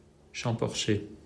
Champorcher (French: [ʃɑ̃pɔʁʃe]
Fr-Champorcher.mp3